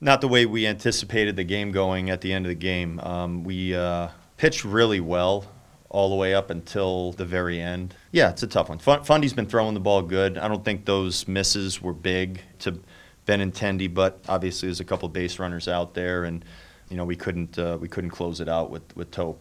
Twins manager Rocco Baldelli says this is a tough loss to swallow.